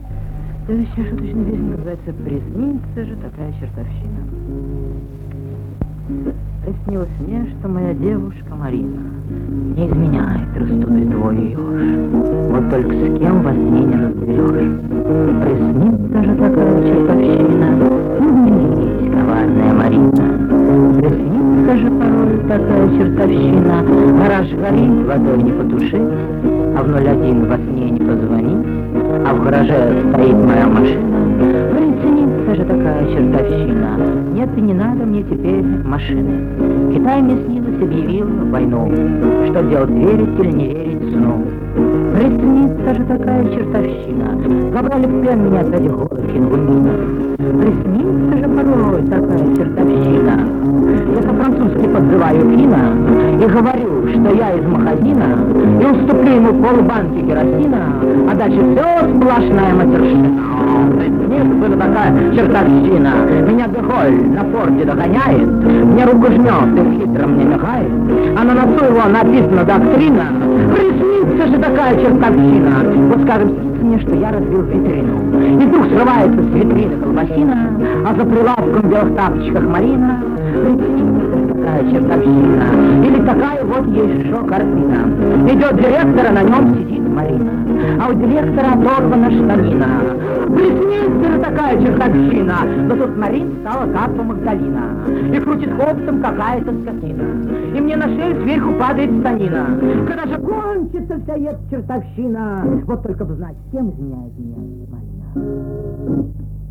Качество, конечно ..... :(